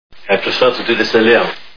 Pink Panther Movies Movie Sound Bites